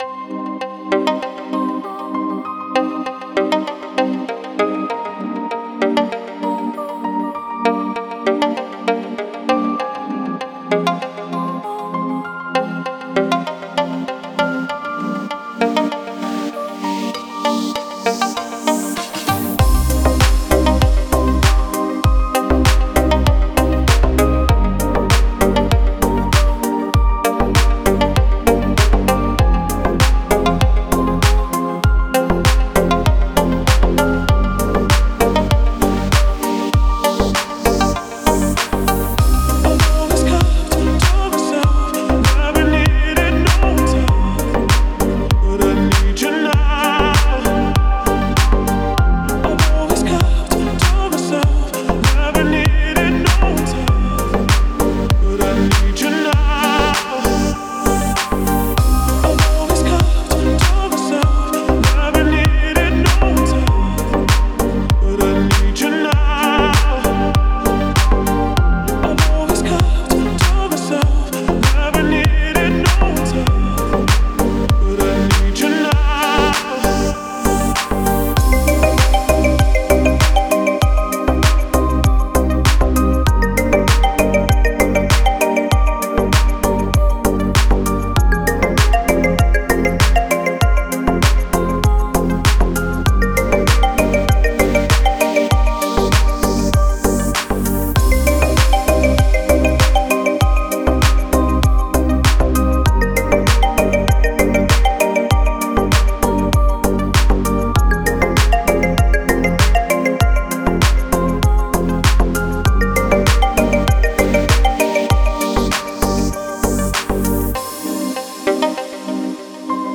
приятные треки , красивая музыка